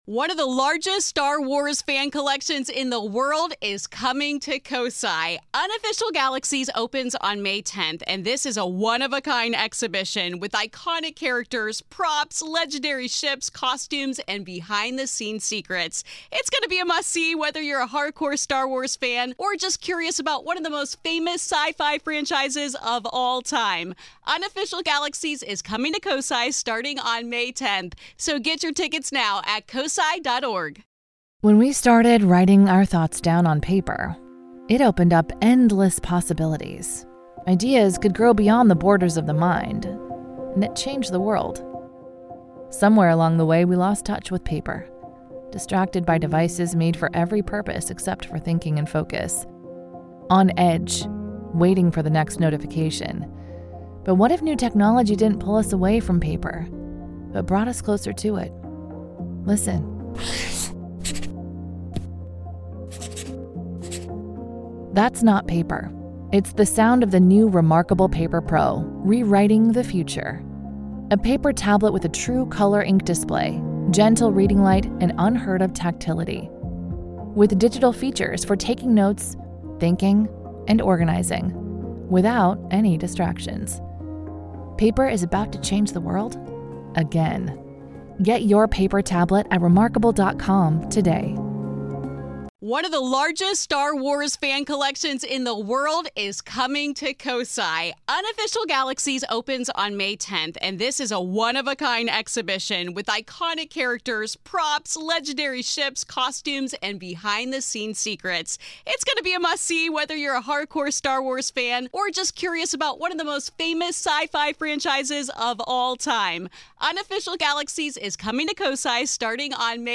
I am joined by you the listener for the new, weekly regular listener show where I go through your emails, DM's and take calls on the UFO topic and related phenomena!